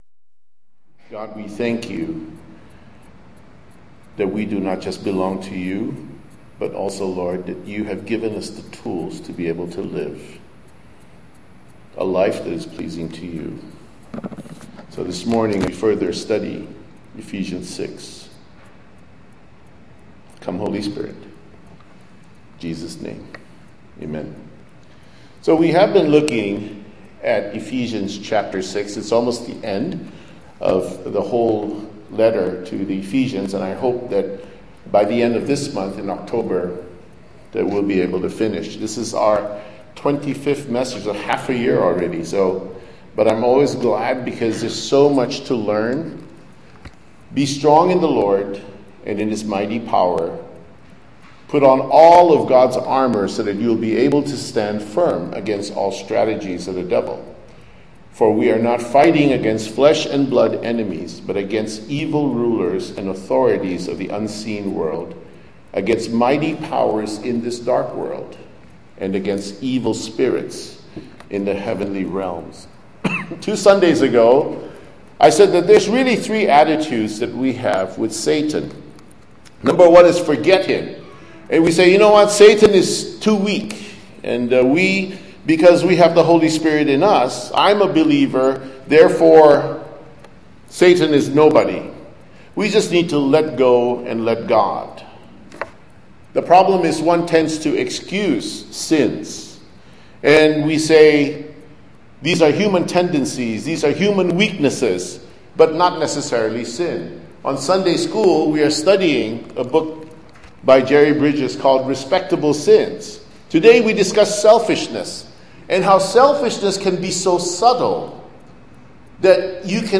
Sermon Topics: Holy Spirit Empowers